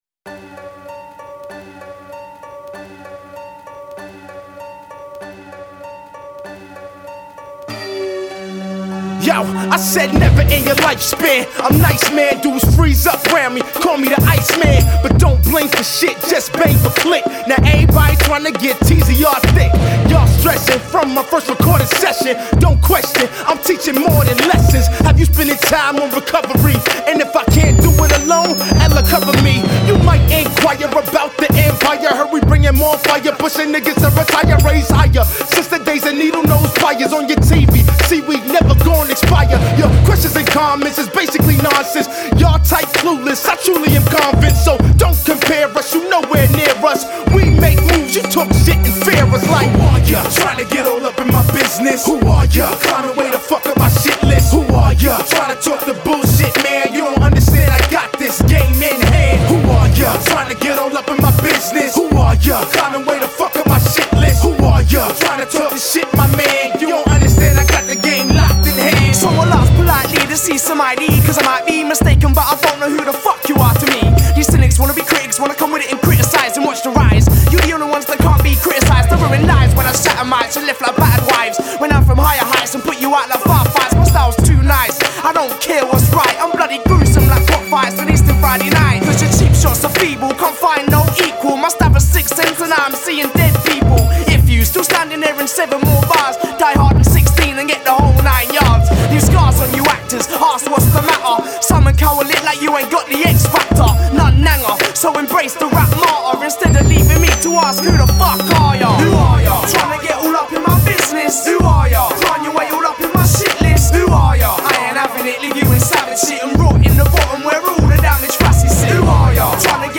2 Styl: Hip-Hop Rok